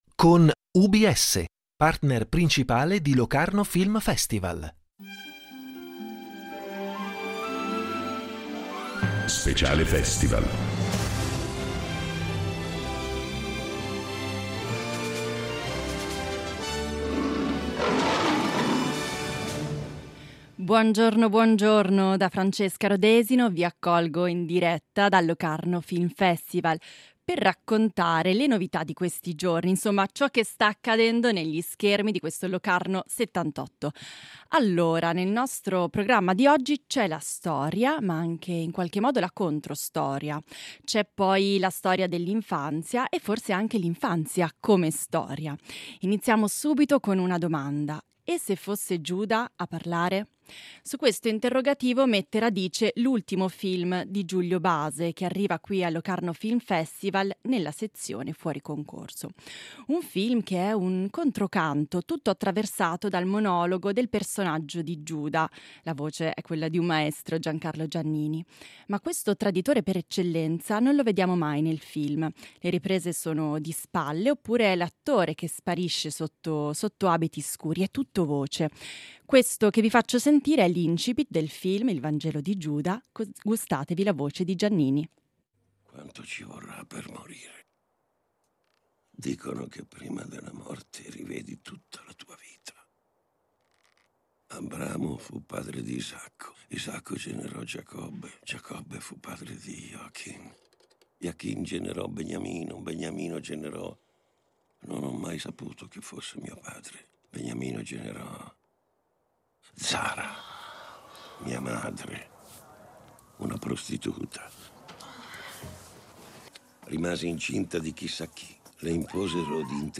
In diretta dal Locarno Film Festival